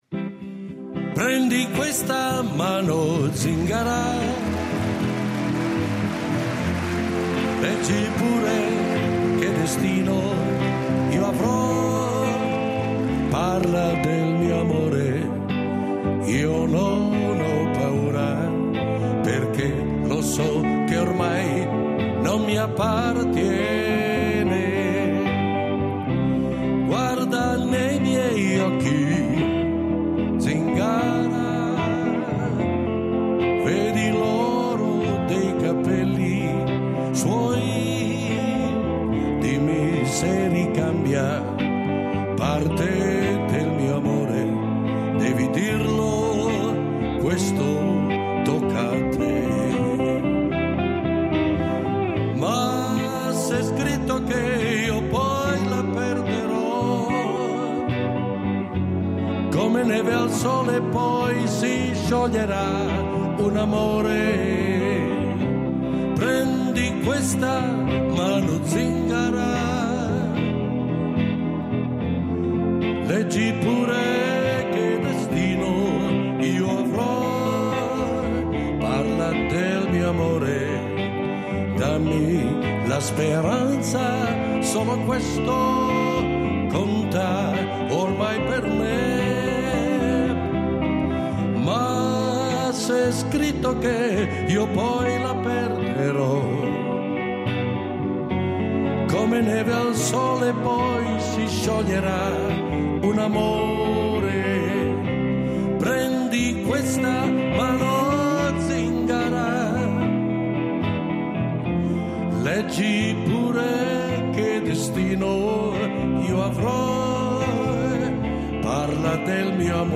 Le due puntate di “Laser” propongono dei momenti scelti della serata pubblica organizzata da Rete Due, all’Auditorio Stelio Molo, lo scorso 18 marzo.
Un’occasione per proporre al pubblico molta musica, ma anche ricordi e analisi di una stagione, gli anni ‘60, in cui è fiorita una cultura musicale popolarissima in un momento di rinascita economica e di grande fermento sociale.